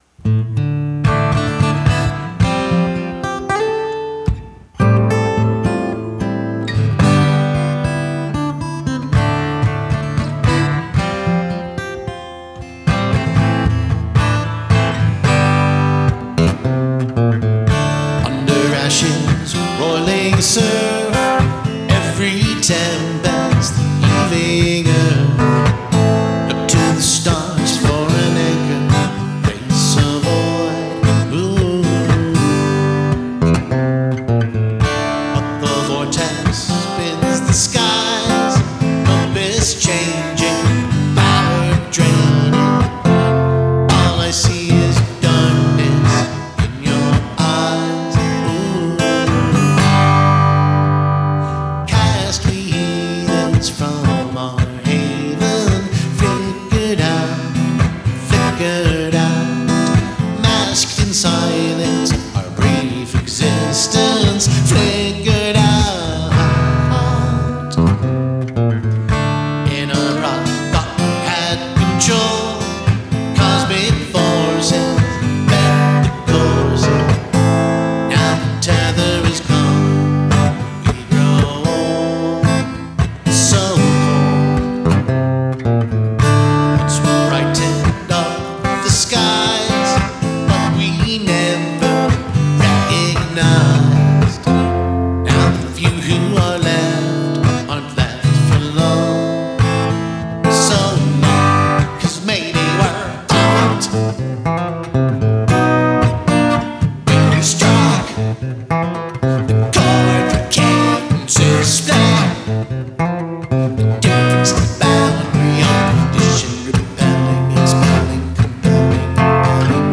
Tempo: 112